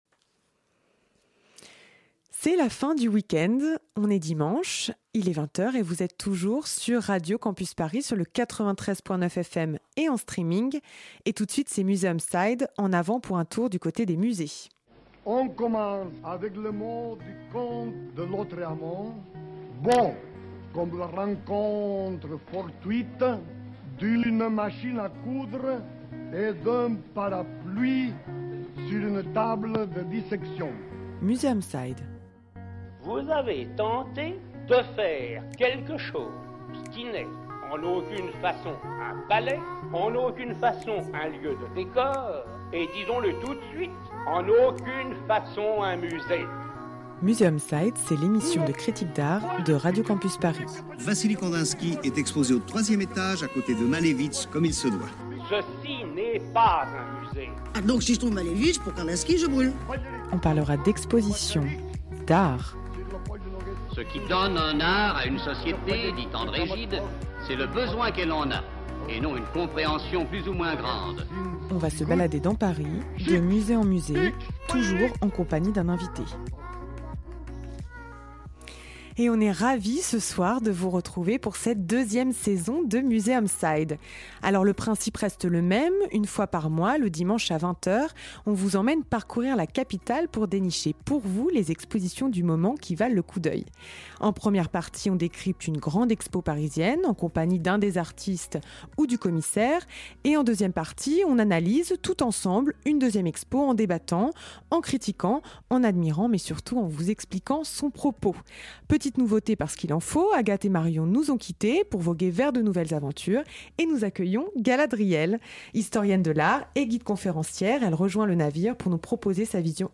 Comme l'année dernière, nous recevrons une fois par mois un commissaire d'exposition ou un artiste qui viendra nous expliquer comment il a conçu l'exposition dont il a la charge. En deuxième partie, nous évoquerons une autre exposition parisienne du moment en débattant toutes ensemble autour de la table